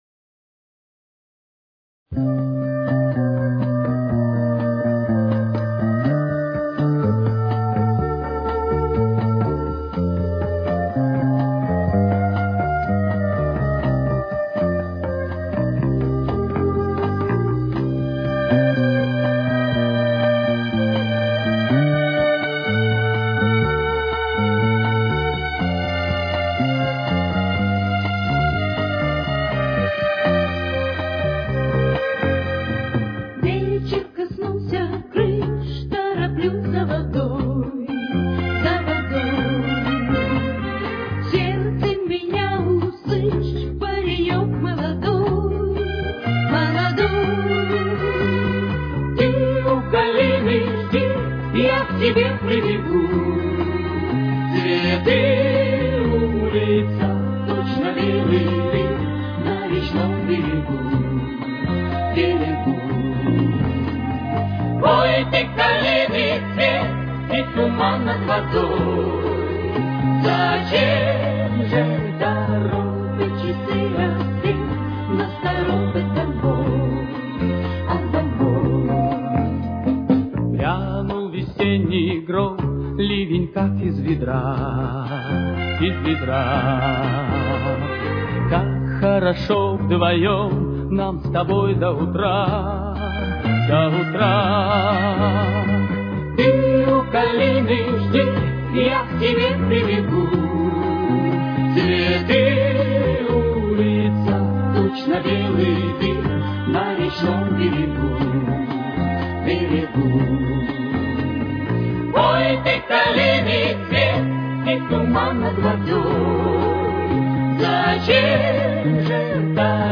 с очень низким качеством (16 – 32 кБит/с)
Тональность: До минор. Темп: 125.